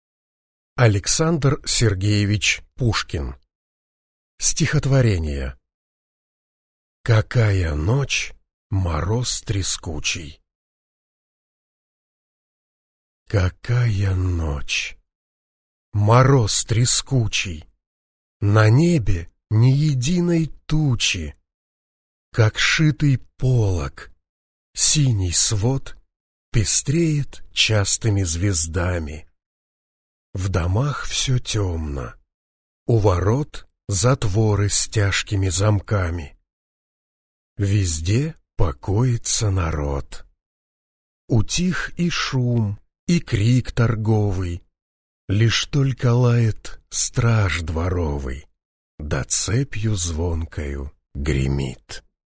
Аудиокнига Стихотворения | Библиотека аудиокниг